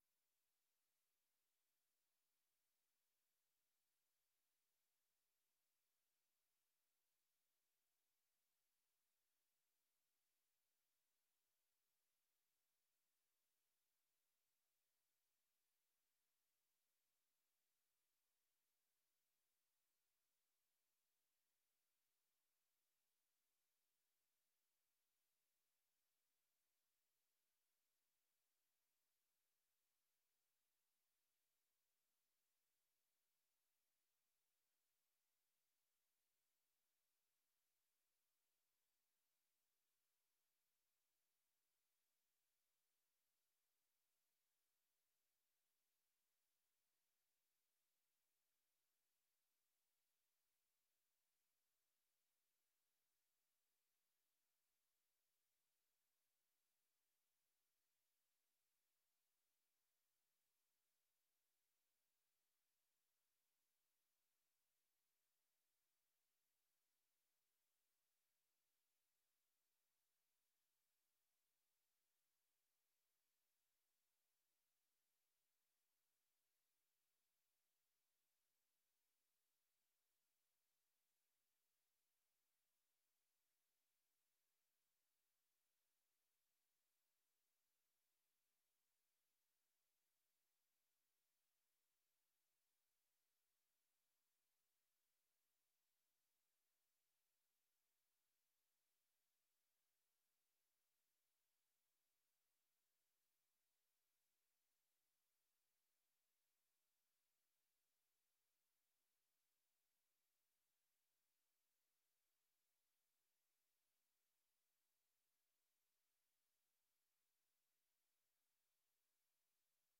Gemeenteraad 18 juli 2024 20:30:00, Gemeente Roosendaal
Download de volledige audio van deze vergadering
Locatie: Raadzaal